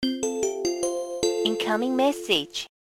알림음 8_ComingMessage.ogg